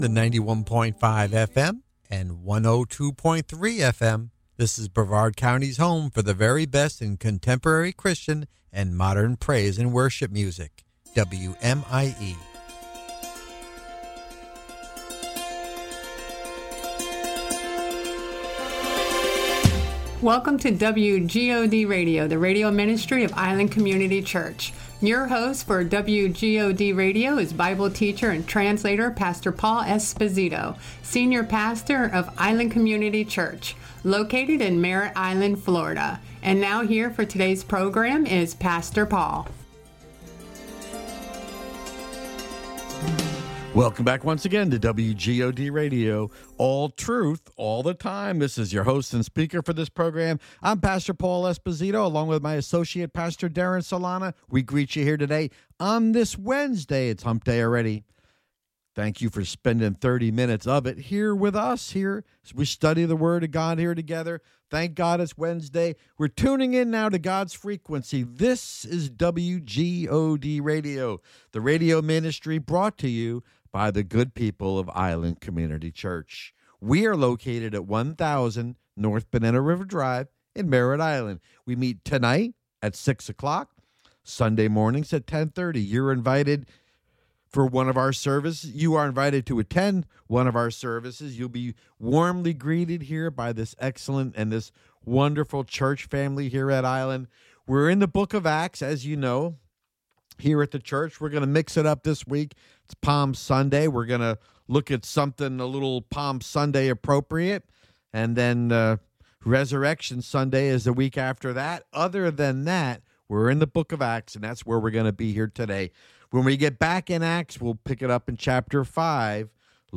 Sermon: "No Other Name" Acts Ch. 4 Part 2